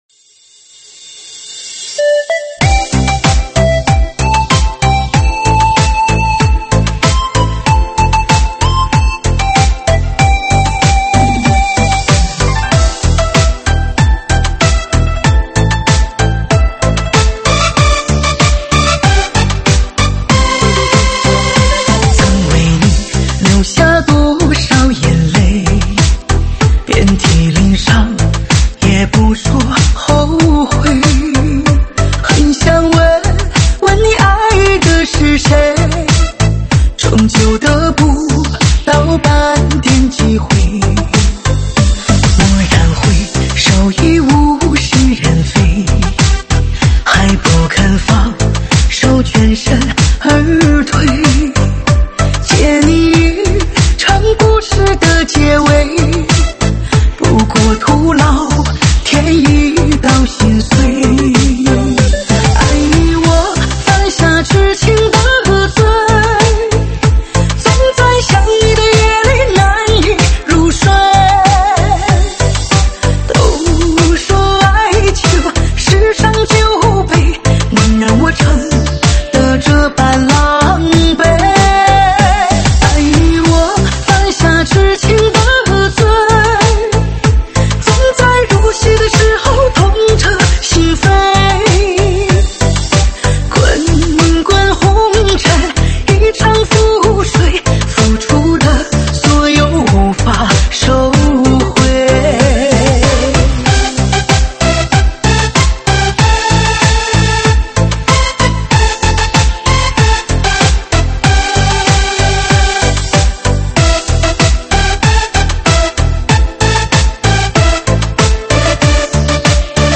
车载大碟
舞曲类别：车载大碟